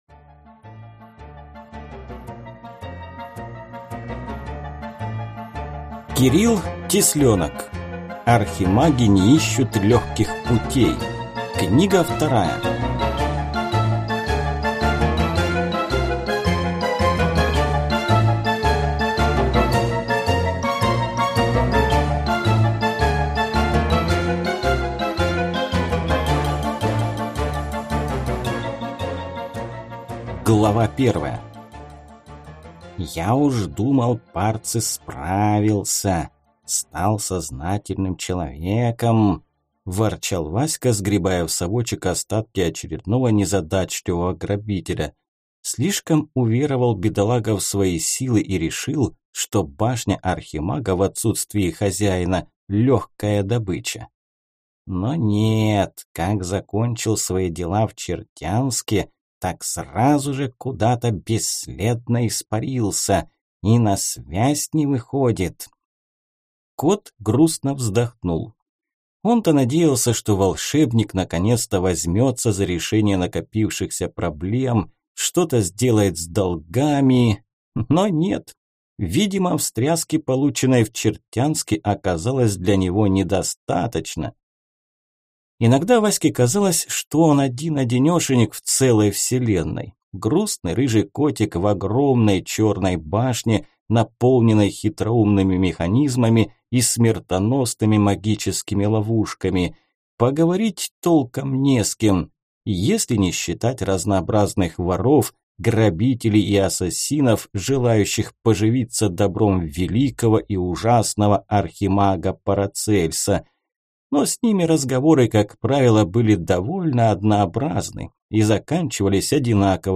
Аудиокнига Архимаги не ищут легких путей. Книга 2 | Библиотека аудиокниг